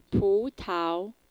so even the male dialogue has female voice.